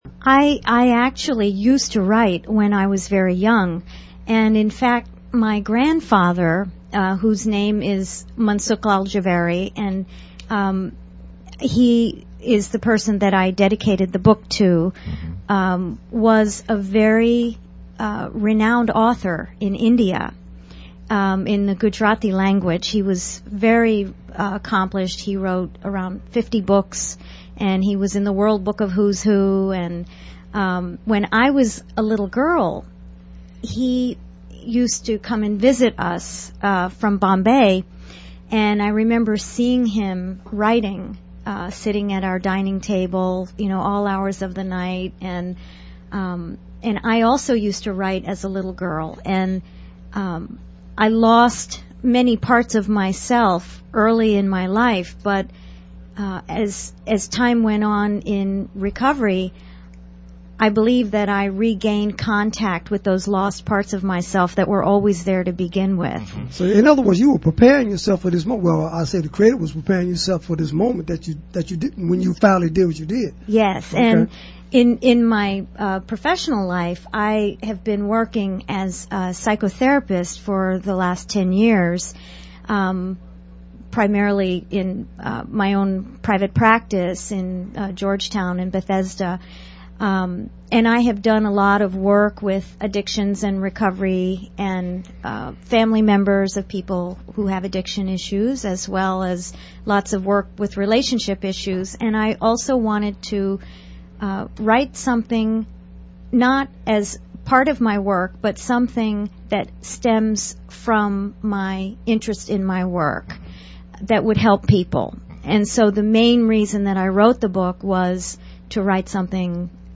RADIO INTERVIEW: NBR FM, NEW YORK, NY - MARCH, 2019